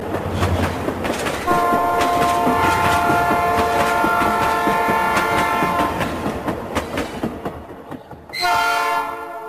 Tren anda y bocina